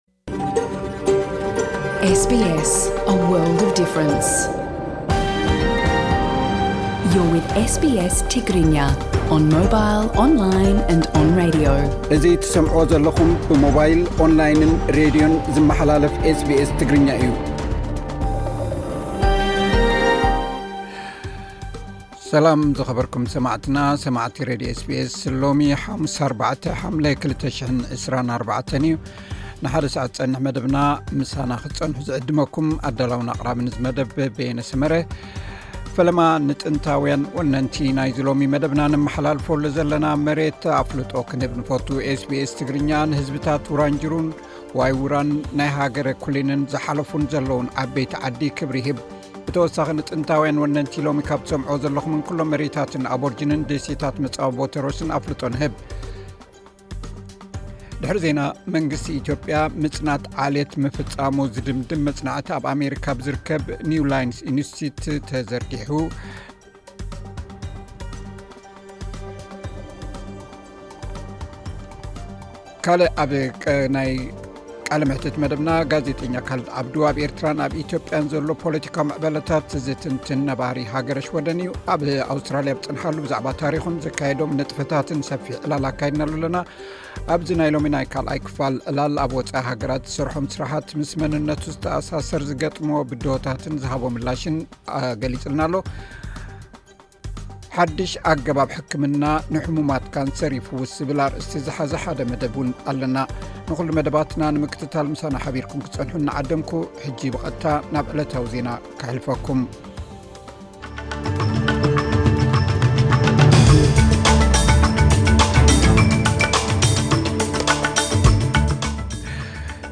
ኣብ ኣውስትራሊያ ኣብ ዝበጽሓሉ ብዛዕባ ታሪኹን ዘካይዶም ንጥፈታትን ሰፊሕ ዕላል ኣካይድናሉ ኣለና።